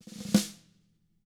TAM3ROLL2D-L.wav